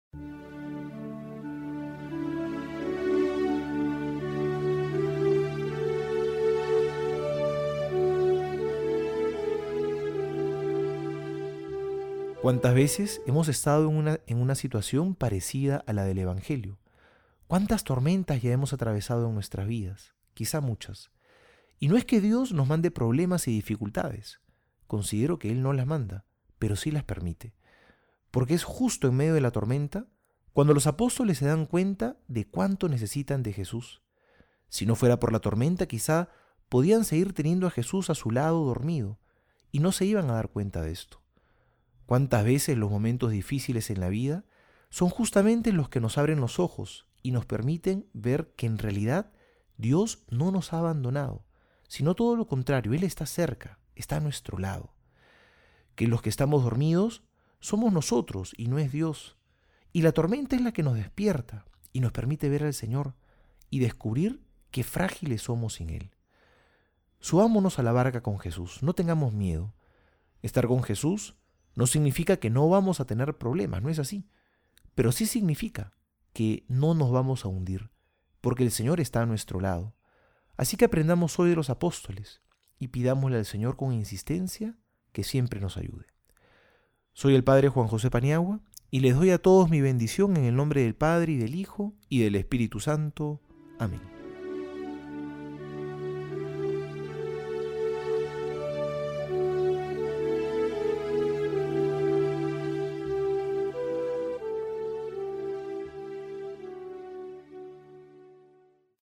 Homilía para hoy:
Martes homilia Mateo 8 23-27.mp3